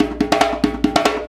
Index of /90_sSampleCDs/Roland LCDP14 Africa VOL-2/PRC_Af.Hand Drm2/PRC_Djembe Drums
PRC HAND D0C.wav